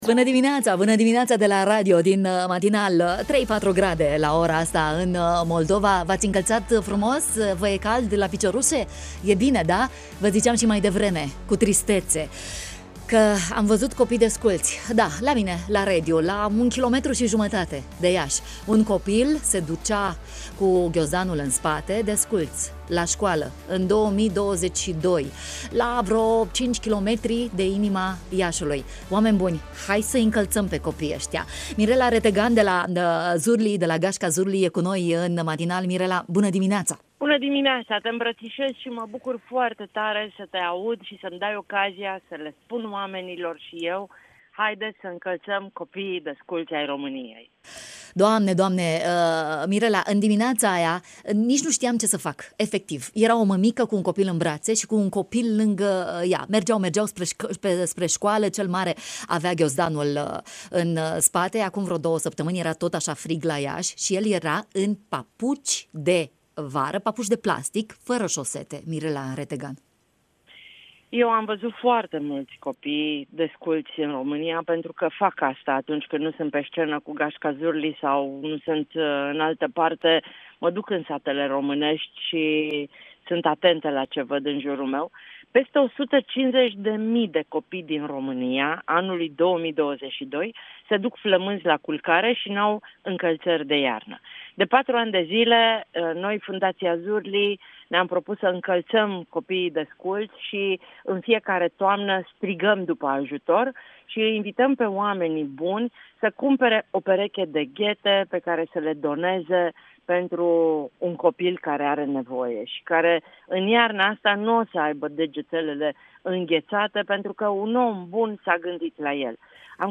Prin telefon, în direct la marinalul de la Radio România Iași